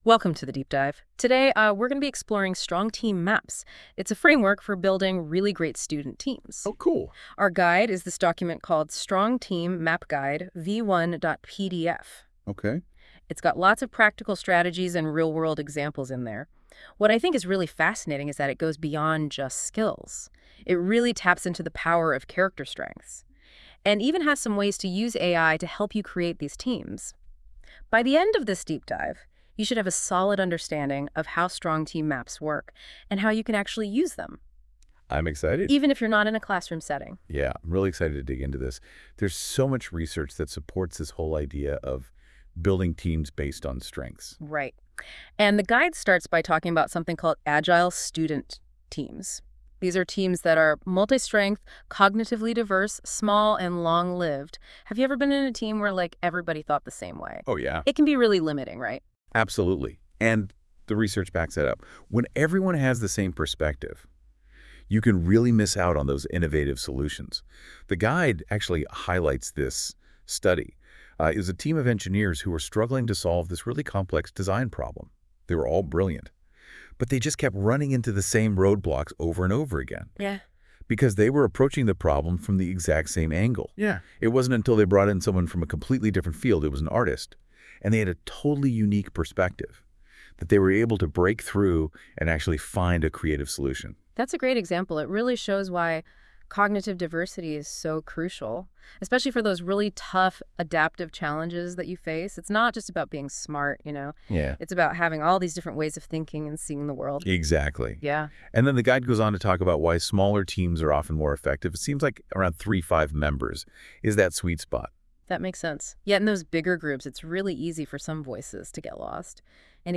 Get a quick, podcast-style breakdown of how Strong Team Maps can help your students collaborate, reduce group drama, and thrive as a team. Created through NotebookLLM .